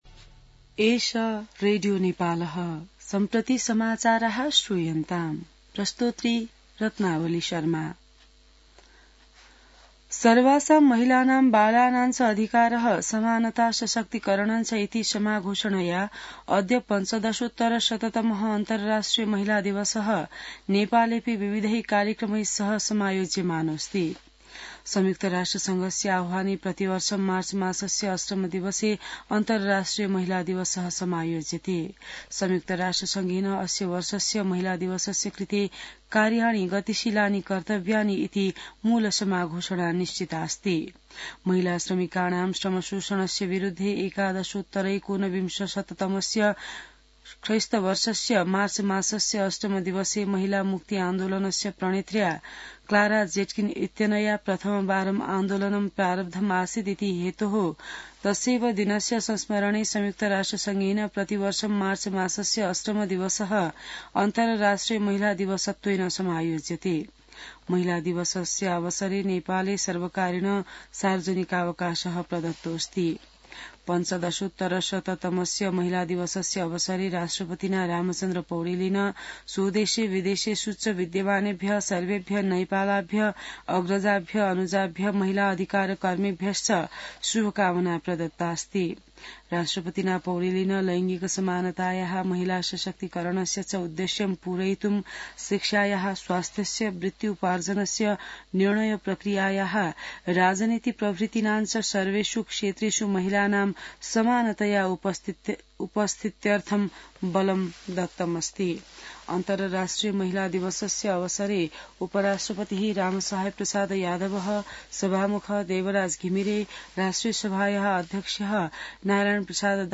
संस्कृत समाचार : २५ फागुन , २०८१